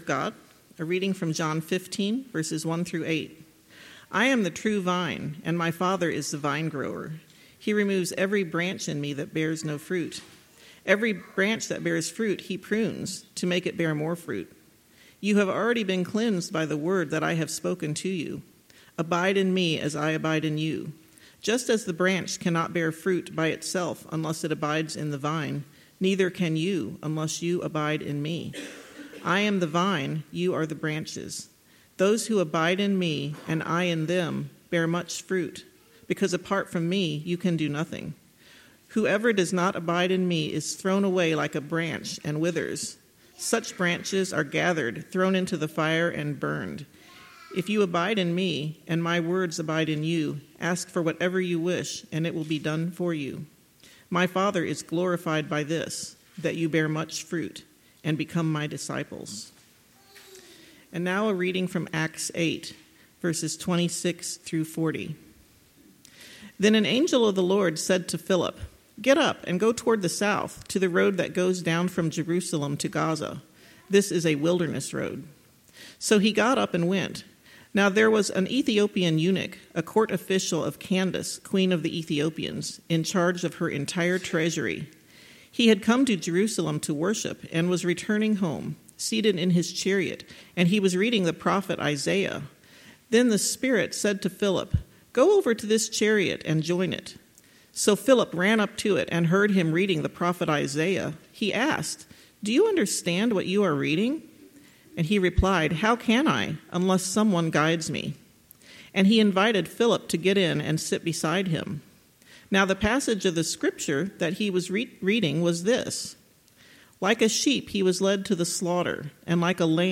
26-40 Service Type: Sunday Morning Topics